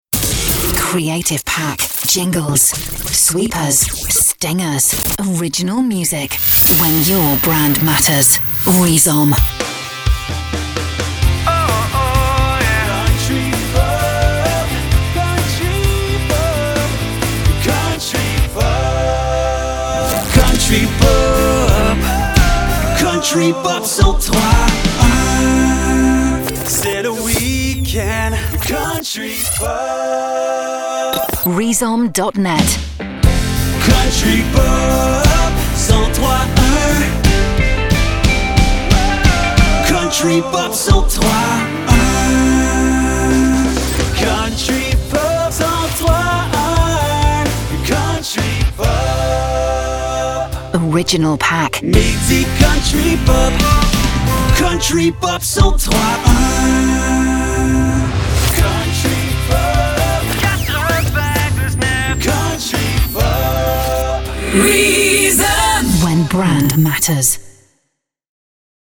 Jingles country Québec , Image sonore chantée.
Package radio, top horaire et signature chantée -